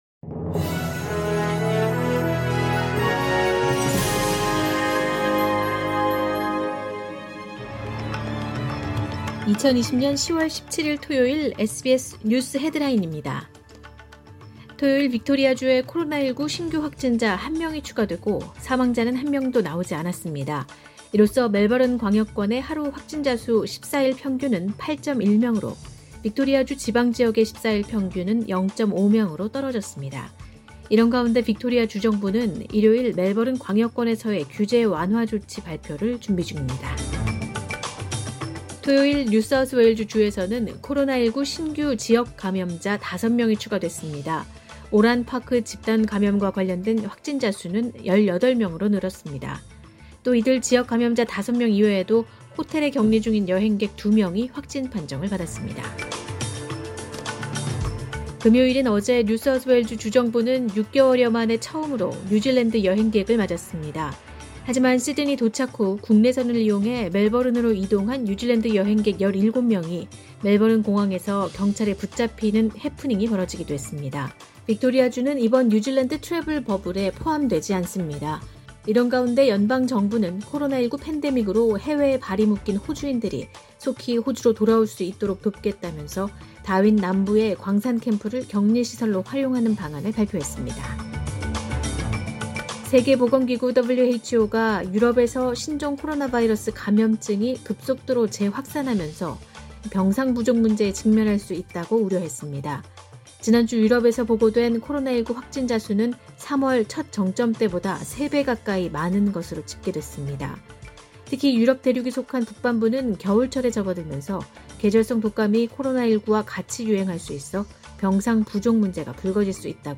SBS News Headlines…2020년 10월 17일 주요 뉴스
2020년 10월 17일 토요일 오전의 SBS 뉴스 헤드라인입니다.